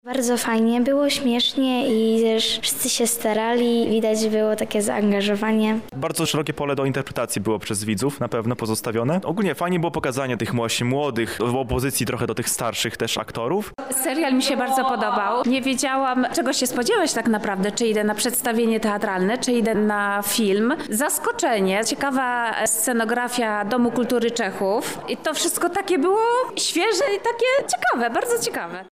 Grupa Oł Maj Gad uchyliła rąbka tajemnicy, którą postanowiliśmy poznać na premierze serialu „Branżowcy”.
Serial „Branżowcy”, relacja